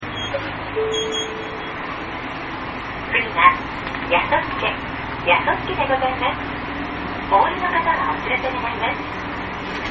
安瀬MpegAudio(39.1KB) やそすけ 北海道中央バス ＪＲ札沼線　石狩当別駅